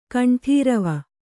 ♪ kaṇṭhīrava